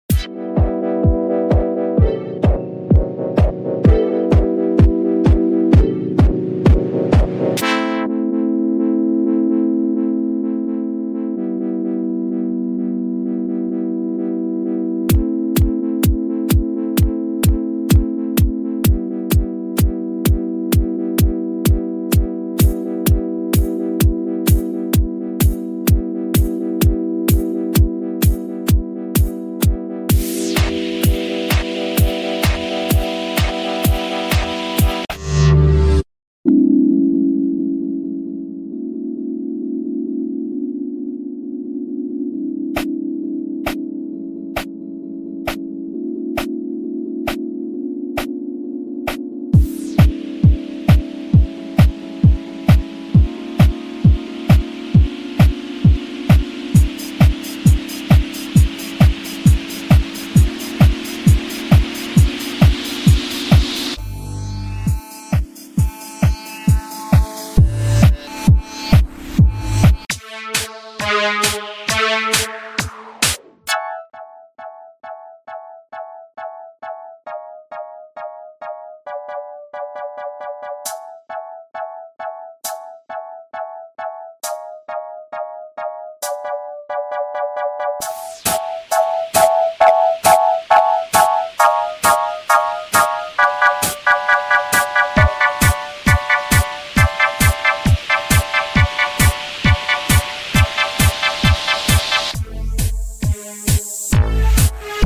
an rundown river sound effects free download